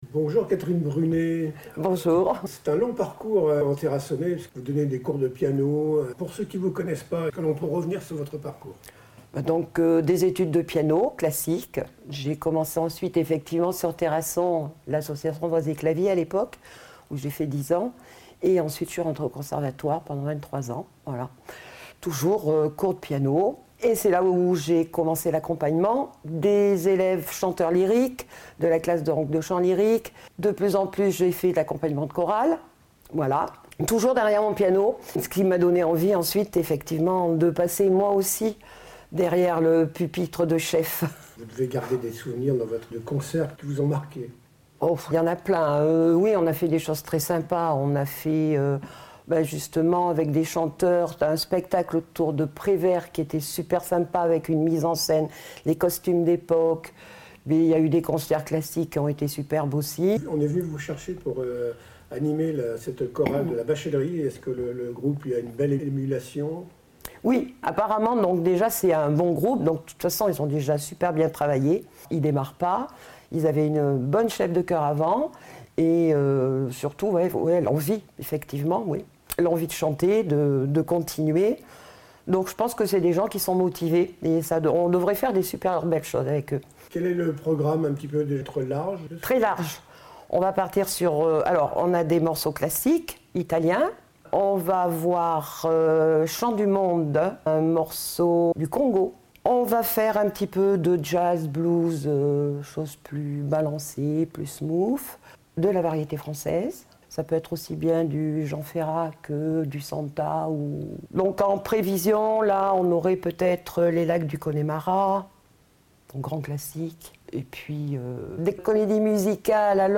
—>>> Interview audio